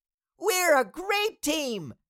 Cartoon Little Child, Voice, We Are A Great Team Sound Effect Download | Gfx Sounds
Cartoon-little-child-voice-we-are-a-great-team.mp3